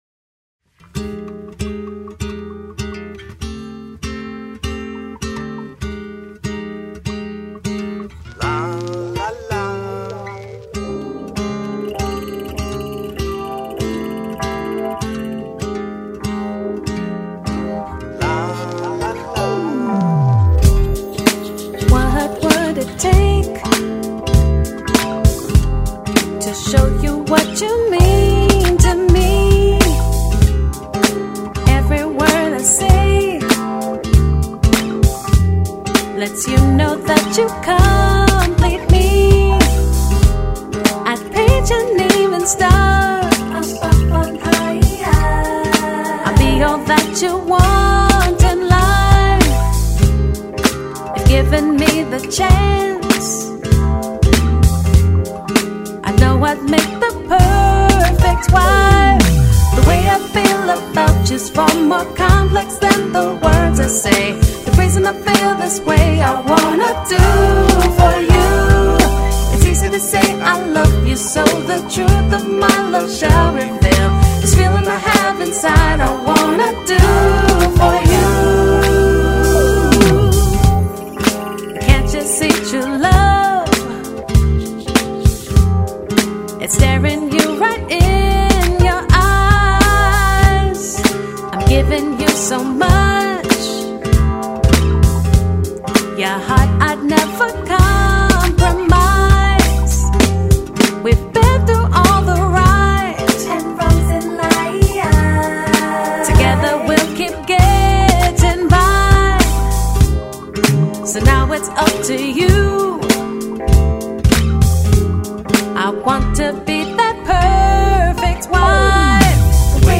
Une fusion Pop Rock Hip Hop Soul explosive
Rock’n’Soul